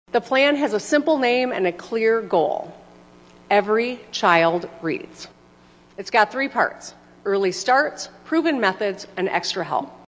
(Lansing, MI)  —  Governor Gretchen Whitmer delivered her eighth and final State of the State address last night, reflecting on her legacy and the issues Michigan is still facing.  She put a focus for part of the speech on improving literacy among children.